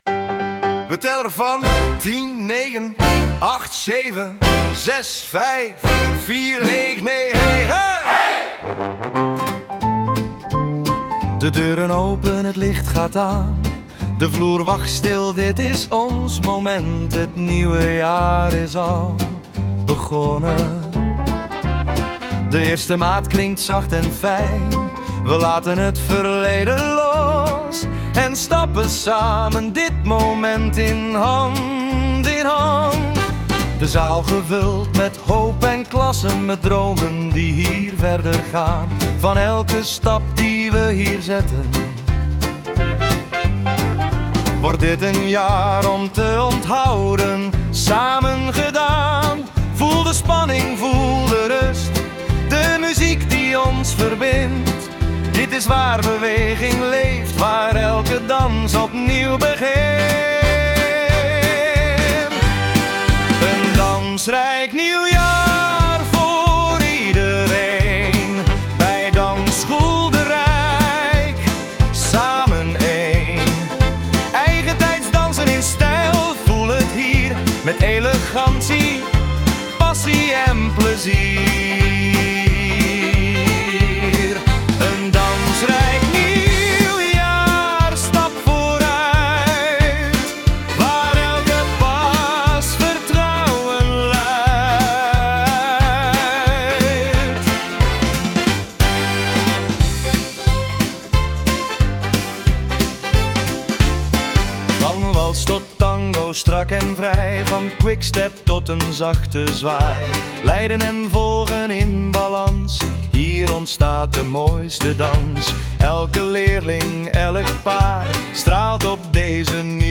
Quickstep Dansrijk Nieuwjaar.mp3